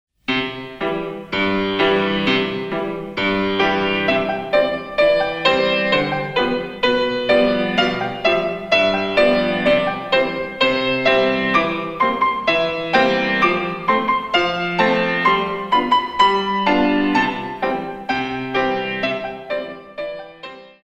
In 2
64 Counts